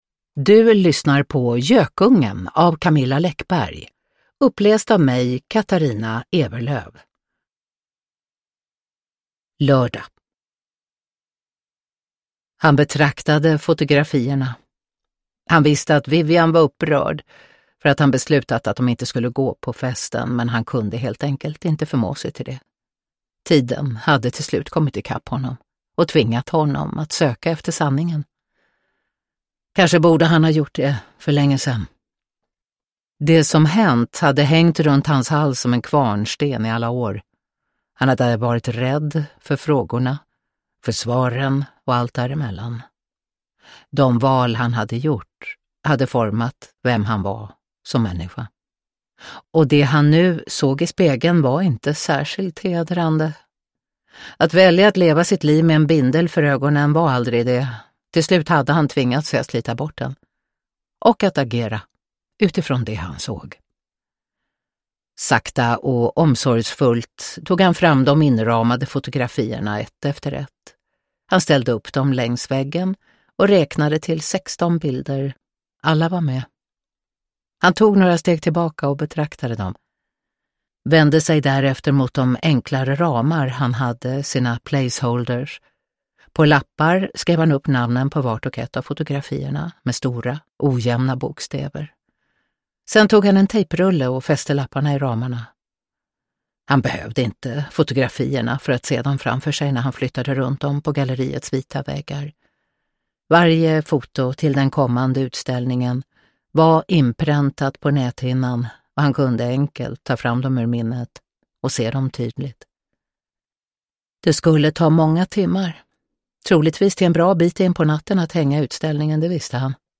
Uppläsare: Katarina Ewerlöf
Ljudbok